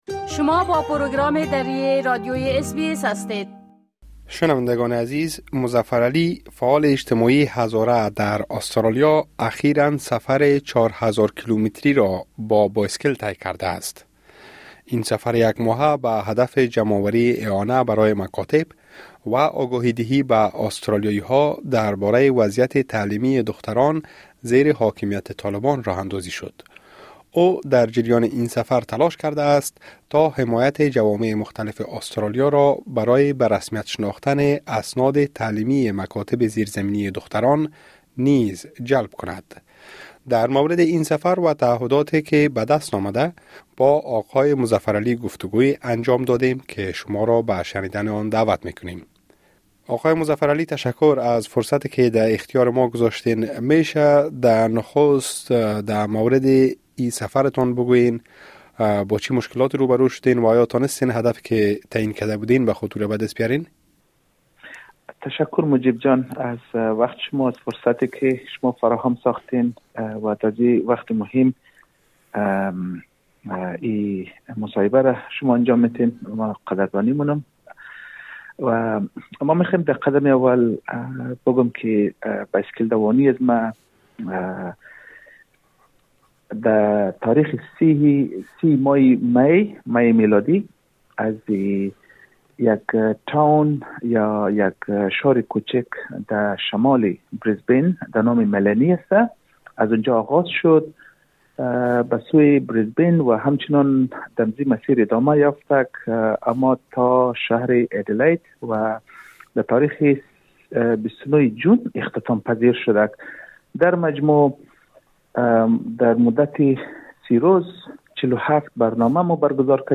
گفتگوی انجام داده‌ایم که شما را به شنیدن آن دعوت می‌کنیم.